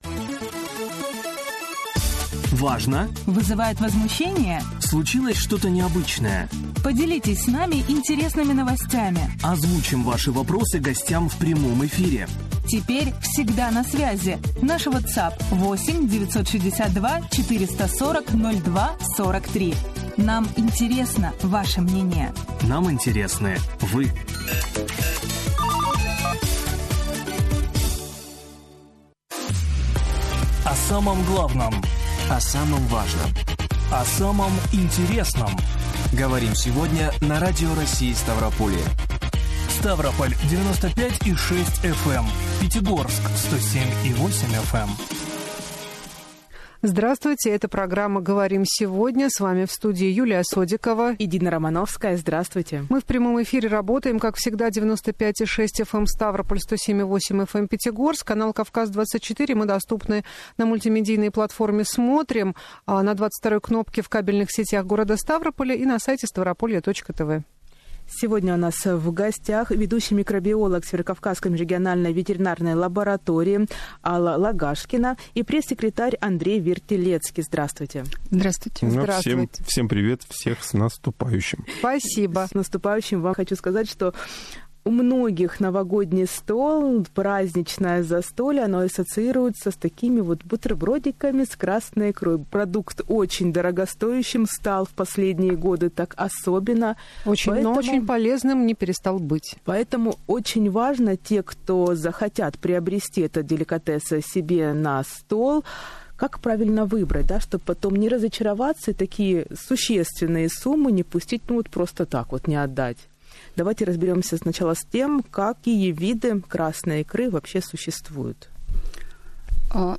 Специалист учреждения говорила не только о правильном выборе икры, но ответила на вопросы слушателей в прямом эфире.